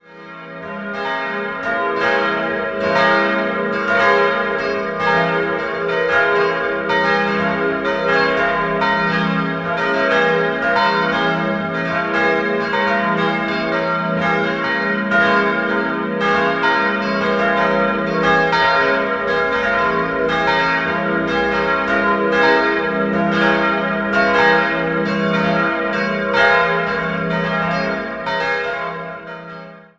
Idealquartett: f'-as'-b'-des'' Die drei kleineren Glocken wurden 1957 von der Firma Bachert in Heilbronn gegossen, die große stammt aus dem Jahr 1564 und ist ein Werk von Christoph Glockengießer aus Nürnberg.